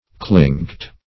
Clink \Clink\ (kl[i^][ng]k), v. t. [imp. & p. p. Clinked
(kl[i^][ng]kt); p. pr. & vb. n. Clinking.] [OE. clinken;